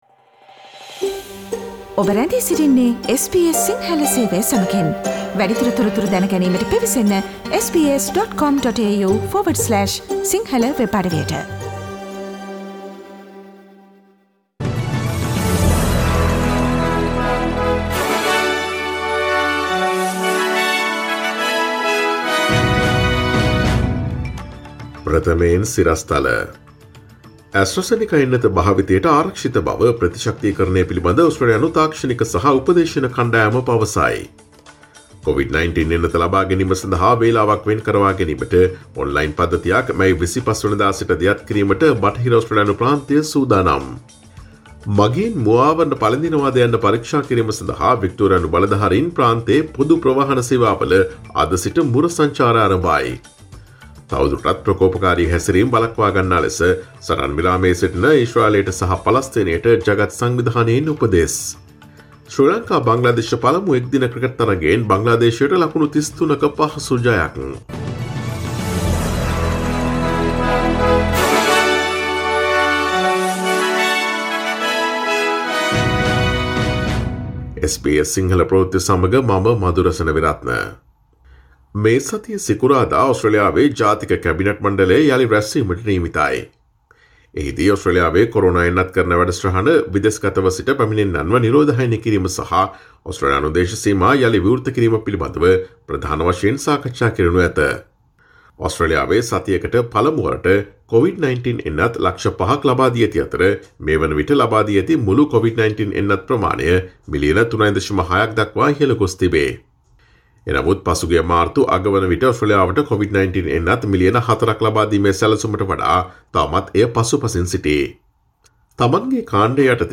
Here are the most prominent Australian, International, and Sports news highlights from SBS Sinhala radio daily news bulletin on Monday 24 May 2021.